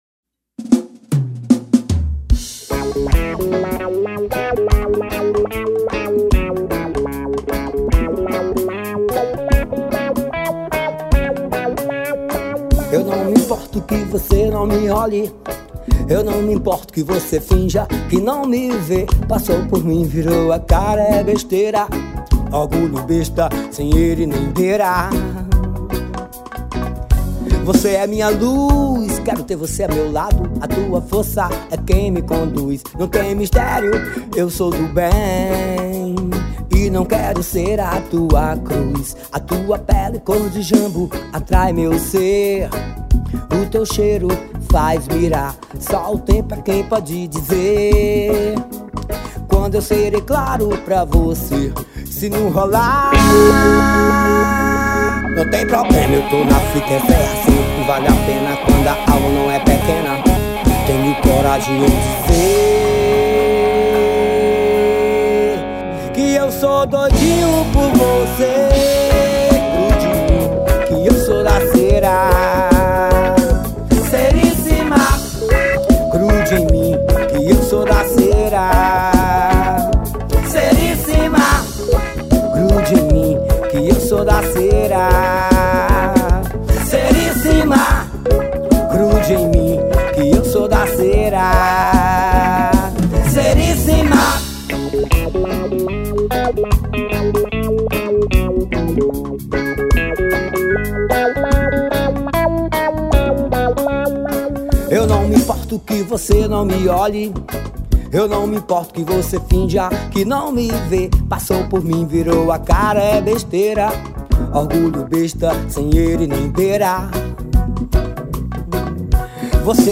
2236   03:11:00   Faixa:     Forró